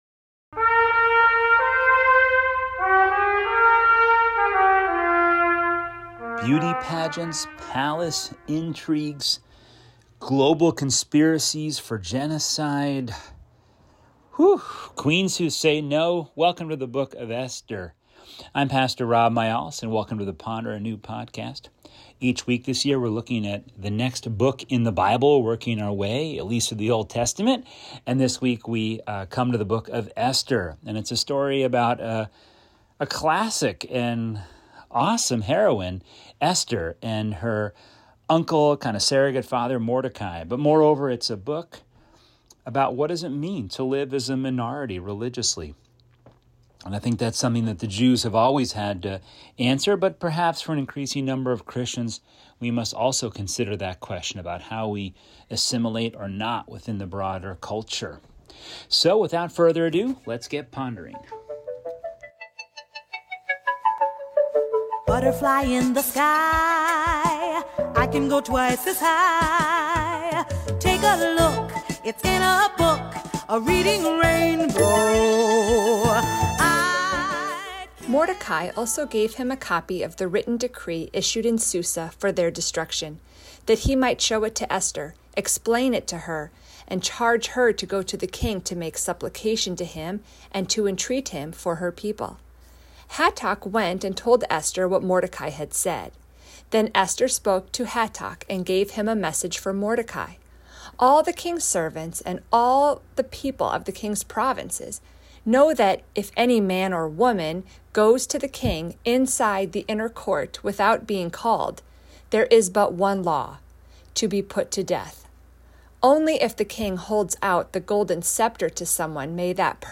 If the sound mixing is off, please let me know.